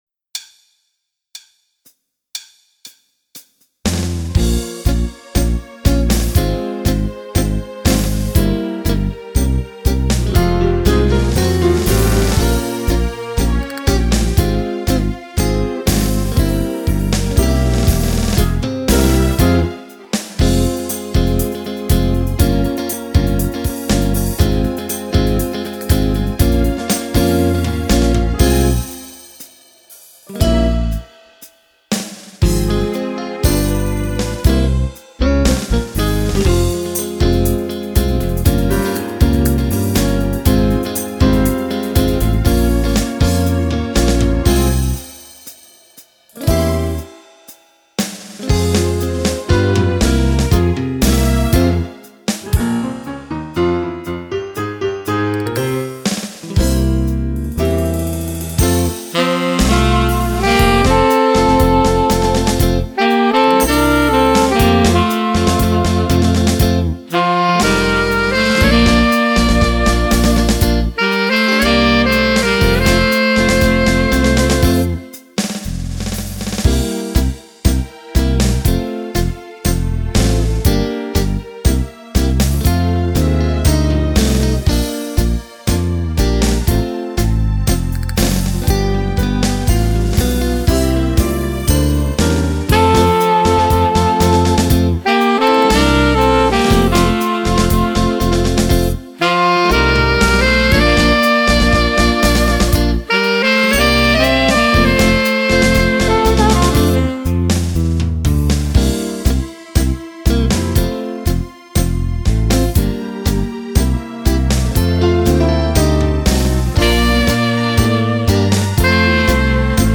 Tango
Uomo